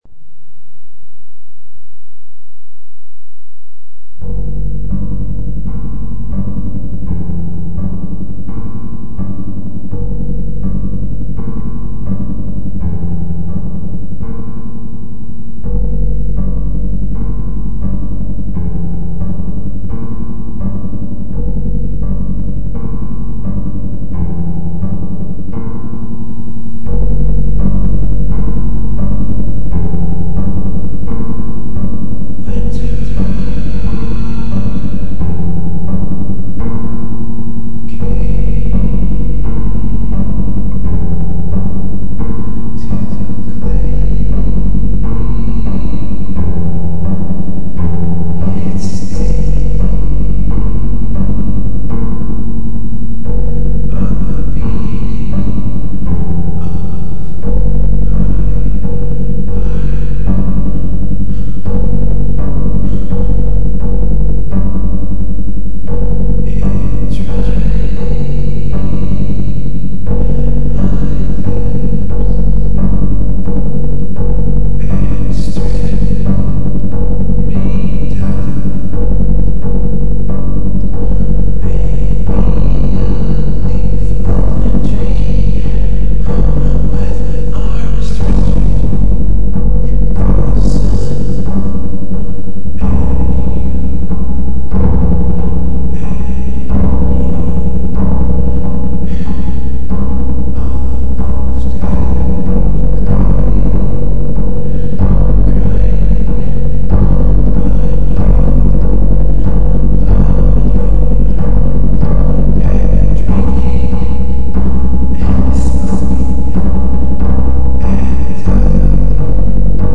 a song even your mother could dance to